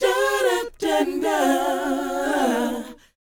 DOWOP G#4D.wav